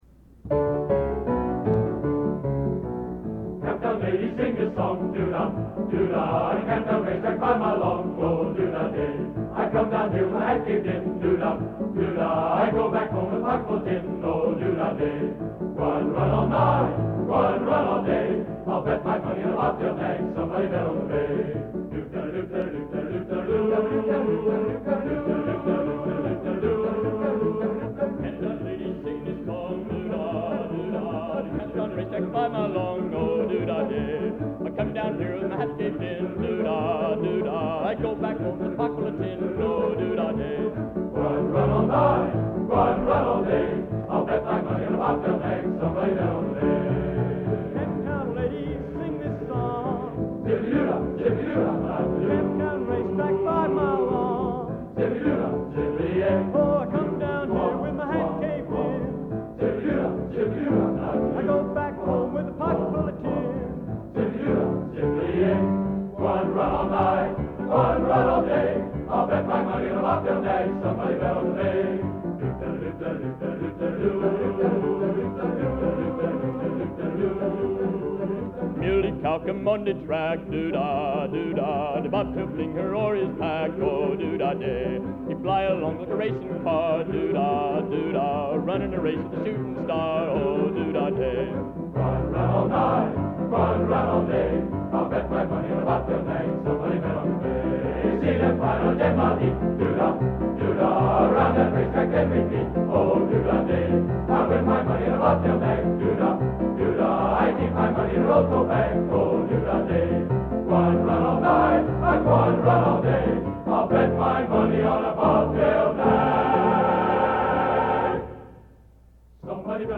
Genre: Popular / Standards | Type: Featuring Hall of Famer